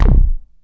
SYNTH BASS-1 0007.wav